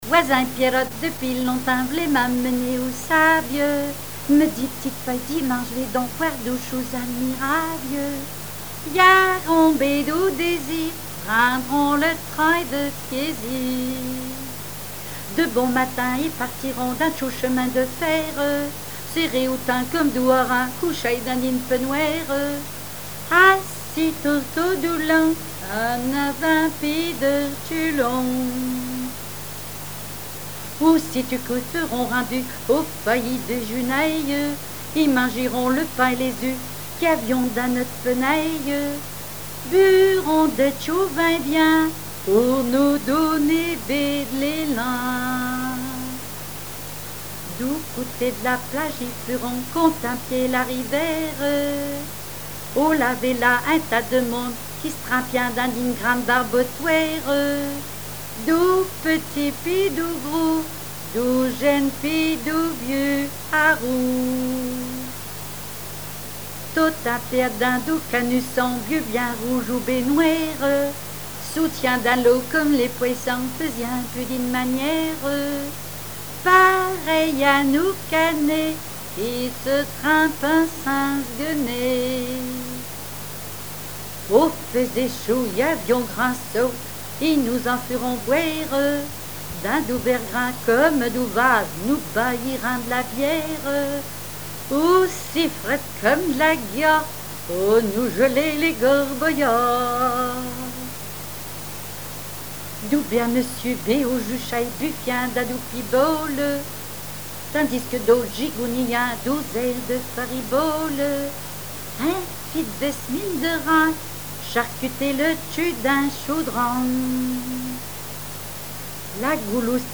répertoire de chansons populaire et traditionnelles
Pièce musicale inédite